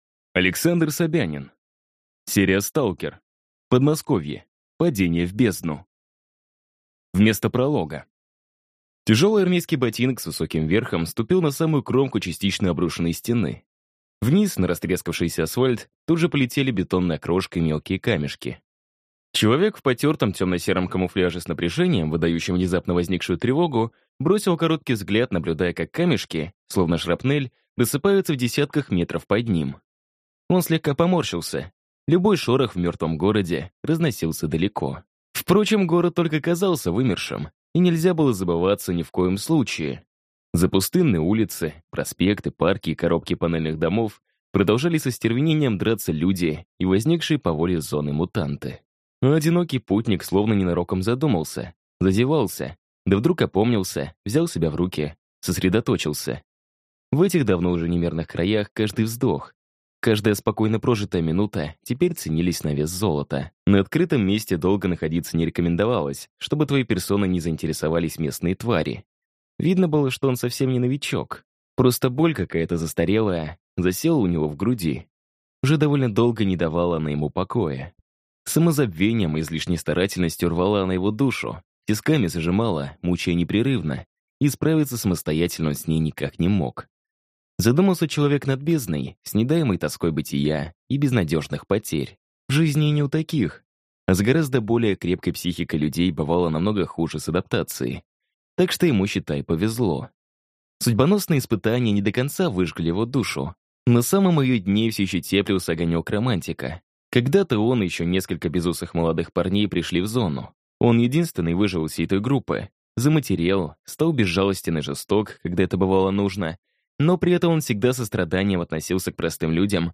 Аудиокнига Подмосковье. Падение в бездну | Библиотека аудиокниг
Прослушать и бесплатно скачать фрагмент аудиокниги